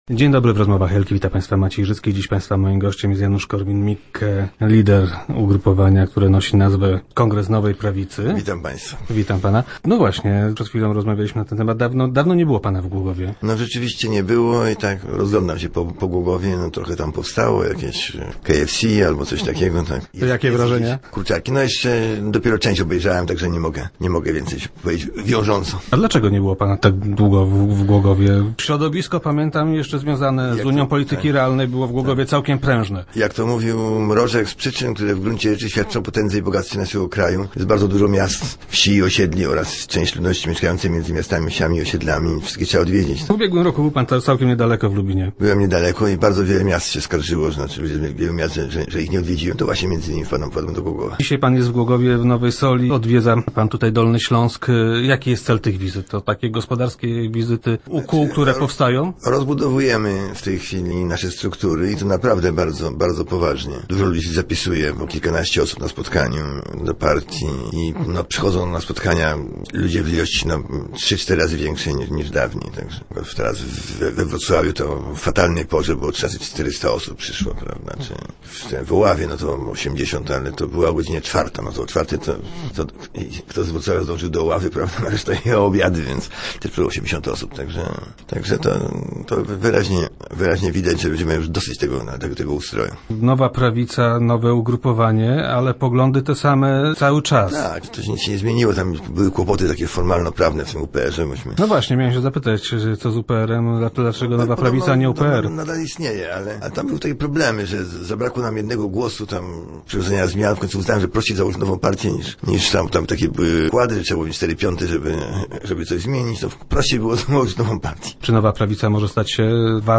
Lider Kongresu Nowej Prawicy był gościem piątkowych Rozmów Elki.